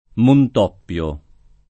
Montoppio [ mont 0 pp L o ]